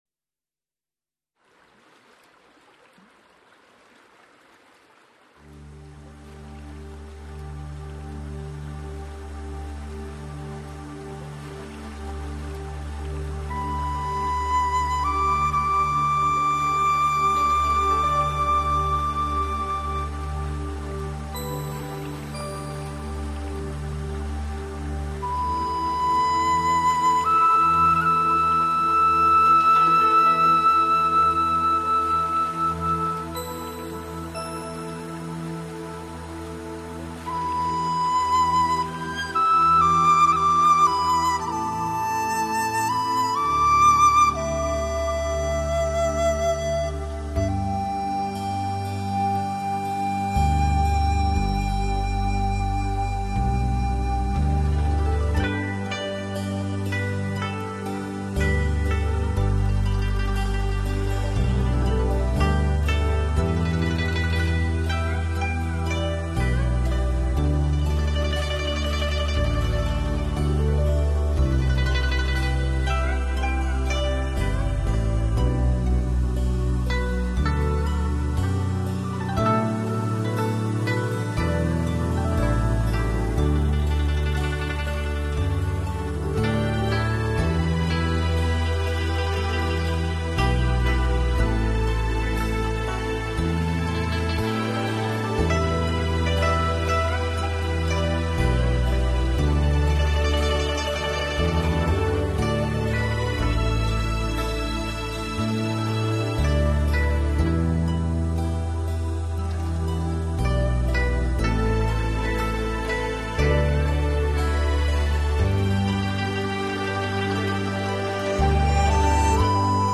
空灵悠远，来自东方遥远的回忆，带给你心灵的慰籍。